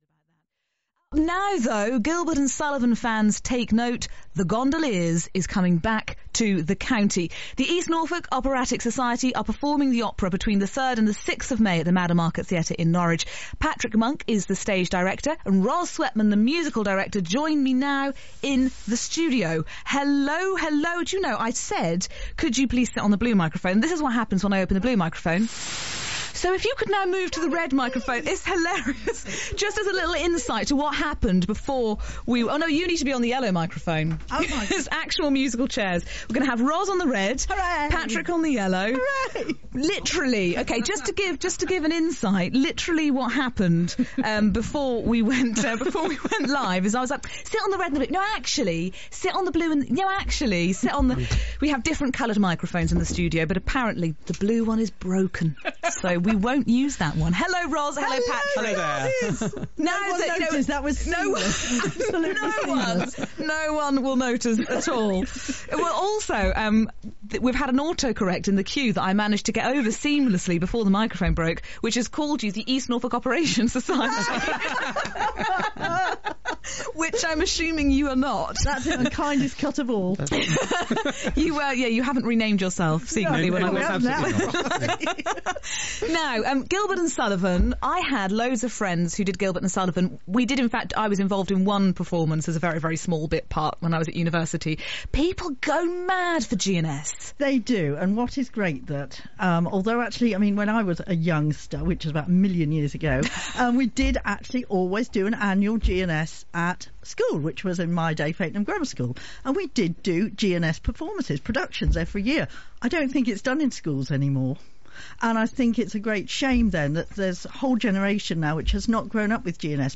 Documents Click on image Programme Cover Click on image Publicity Poster Gondoliers Synopsis Press Review NODA Review Listen to the Publicity Interview broadcast on BBC Radio Norfolk on 25 April 2016